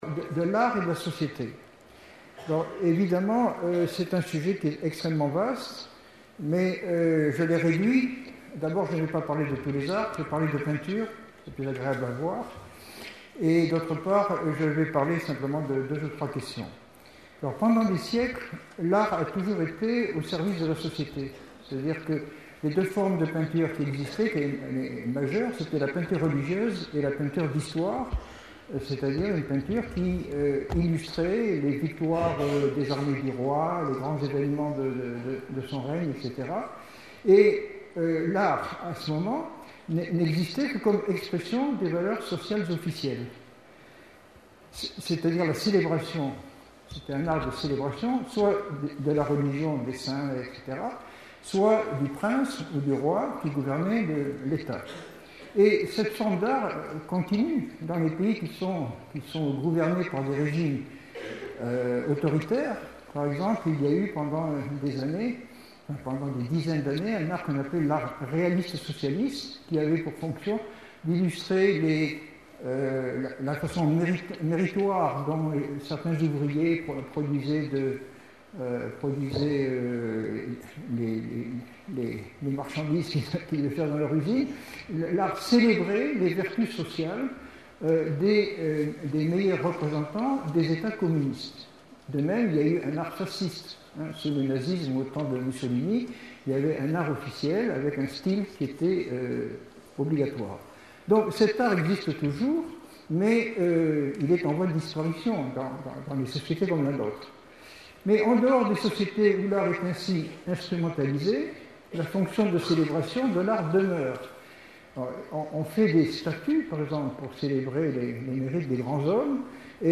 Une conférence de l'UTLS au Lycée Art